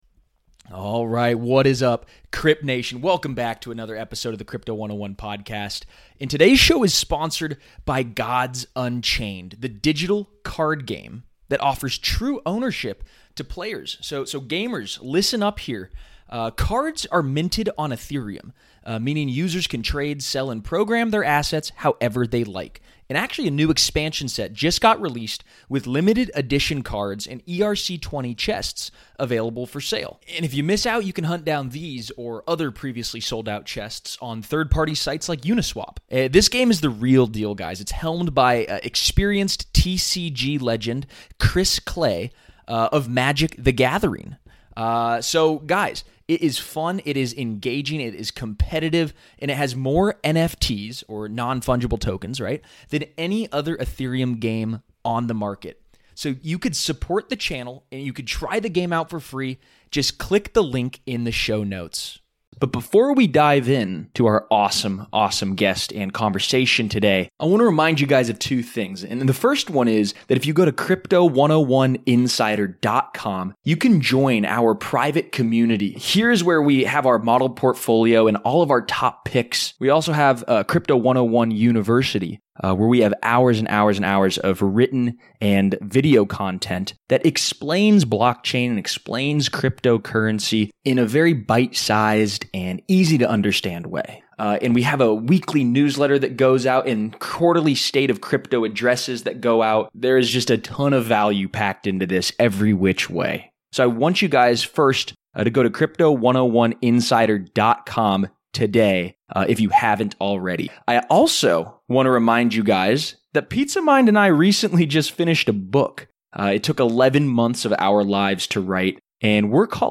In this episode of CRYPTO 101, brought to you by Gods Unchained, we talked to Charles Hoskinson from Cardano.